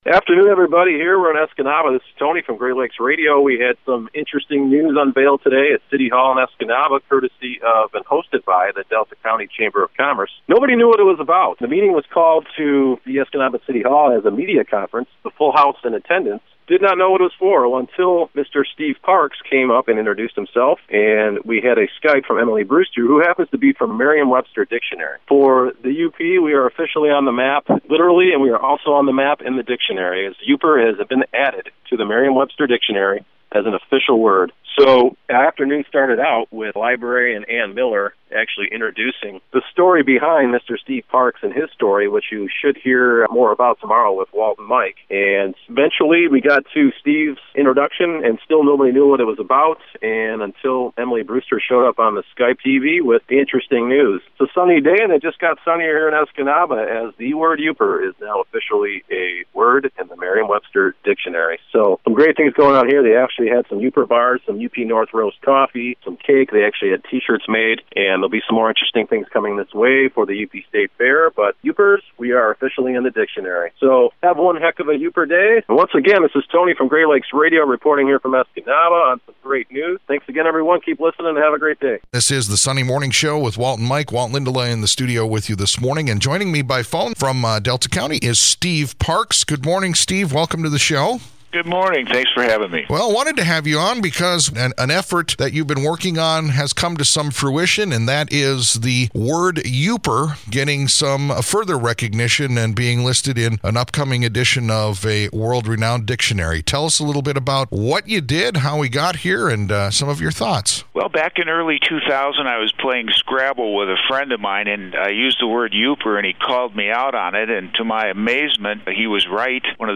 Best in Category – Breaking News Story: Yooper Added to the Dictionary